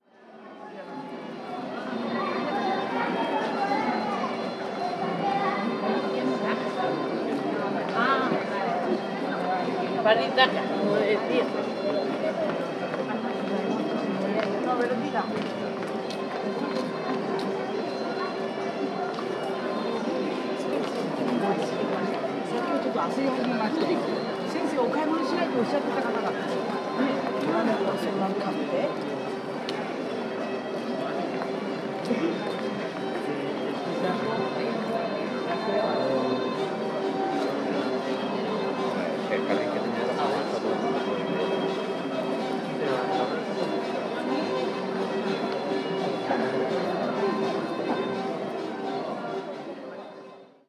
Ambiente de música en las calles de Santiago de Compostela, A Coruña
conversación
folclore
gaita
murmullo
Sonidos: Gente
Sonidos: Ciudad